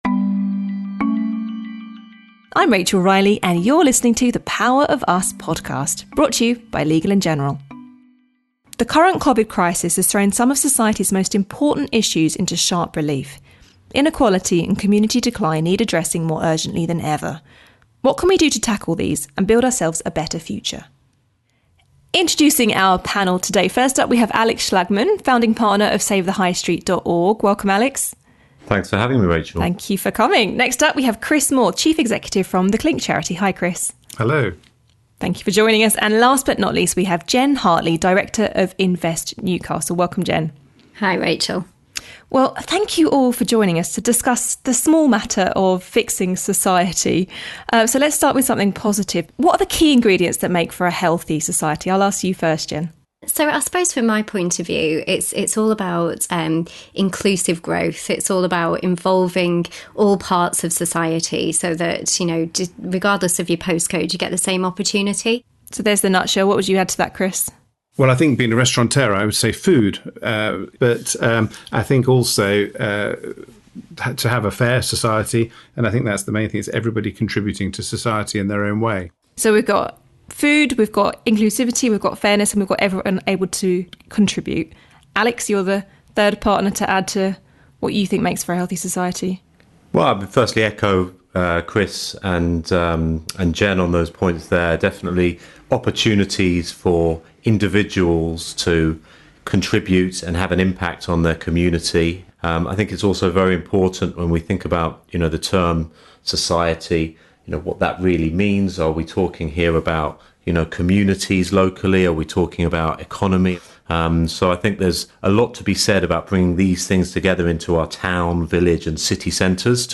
The Power of Us Podcast by Legal & General x The Telegraph As the global pandemic transformed the ways we live and work, the Power of Us Podcast was created by Telegraph Spark and Legal & General, hosted by Rachel Riley. Rachel is joined by a panel each episode to discuss some of the biggest issues facing our society and what we, working together as individuals, businesses and government can do to shape a sustainable vision for the future that can benefit us all.